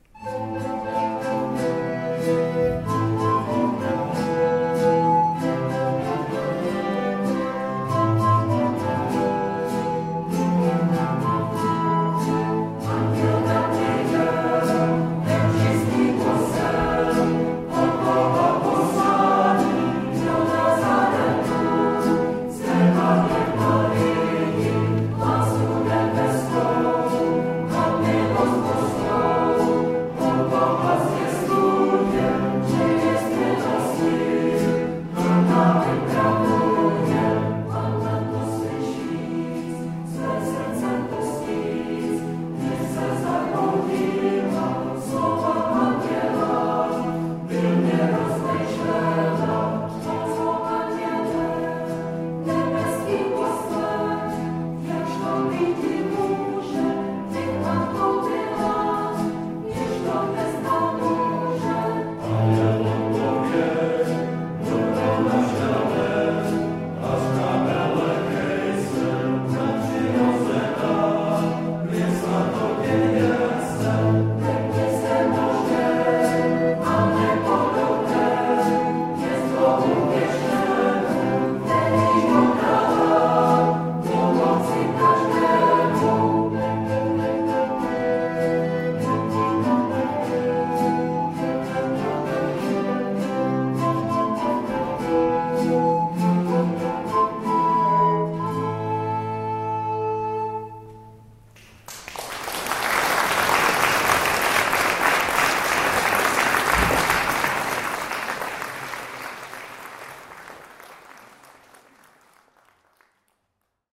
Cantica s Cuthnou odehrály adventní pásmo
Program koncertu byl laděn tentokráte více barokně a v rámci něho interpeti písní "Zavitej k nám dítě milé" vzdali hold Bedřichu Bridel
viola d'amour a housle
vihuela da mano